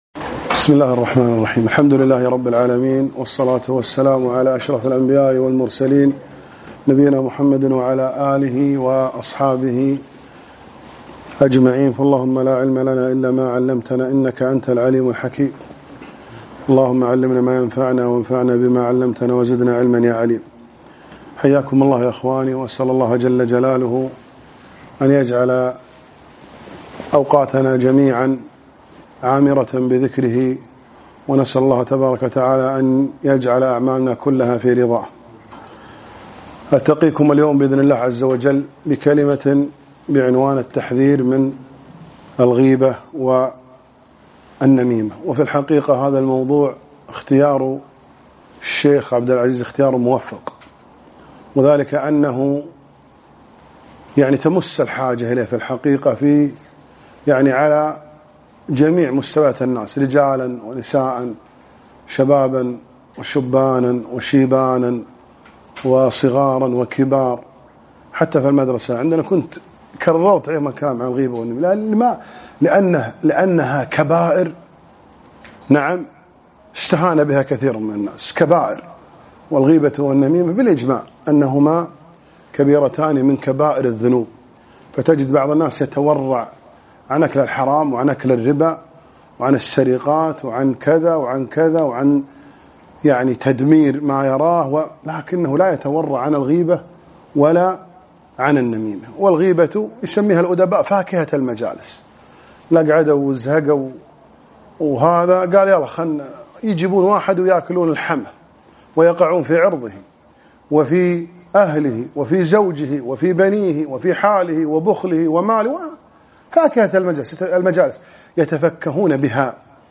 محاضرة - الحذر من الغيبة والنميمة